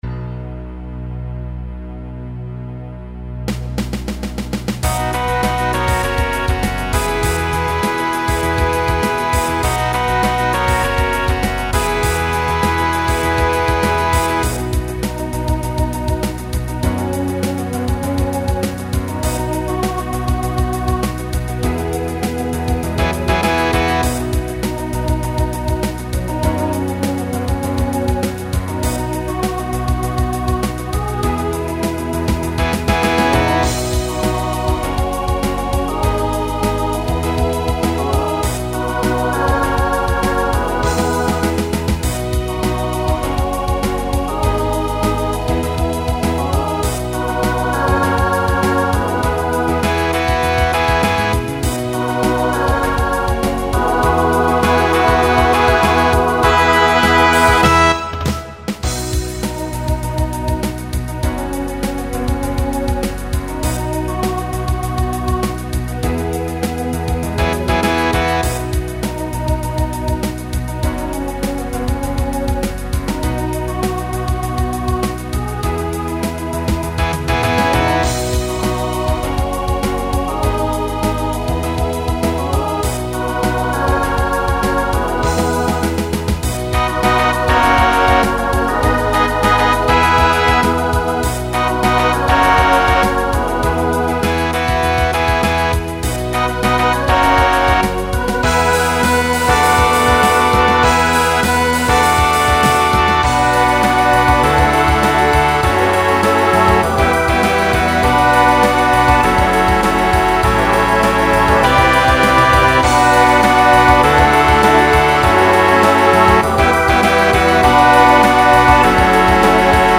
Voicing SATB Instrumental combo Genre Pop/Dance
Mid-tempo